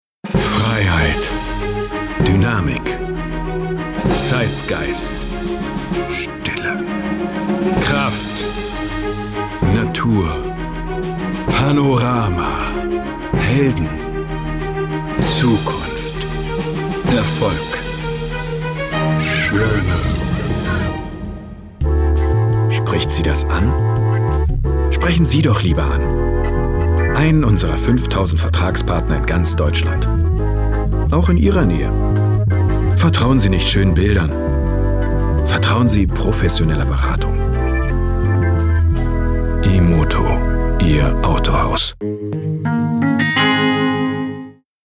sehr variabel, markant
Mittel plus (35-65)
Commercial (Werbung)